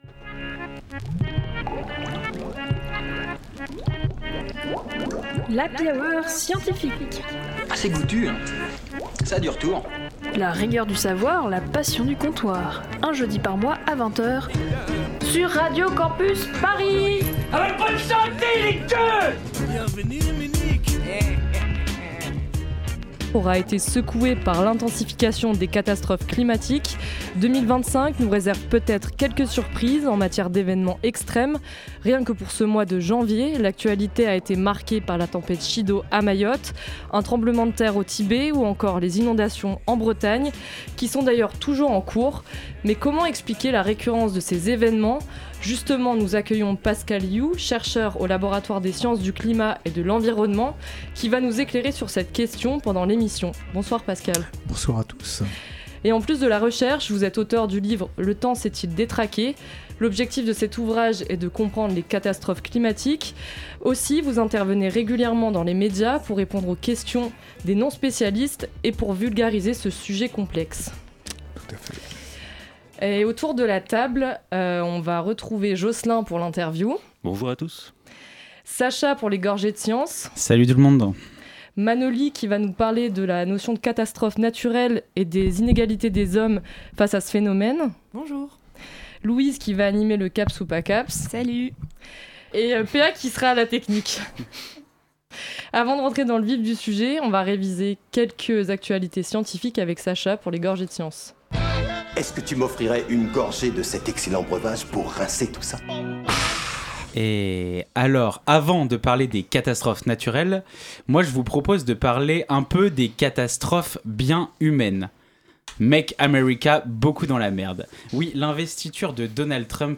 Magazine Sciences